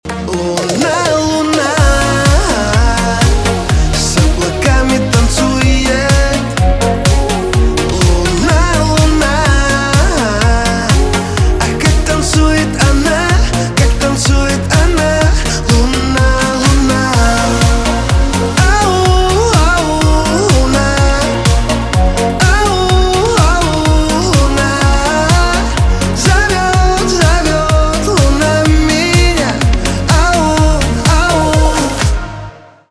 поп
битовые , танцевальные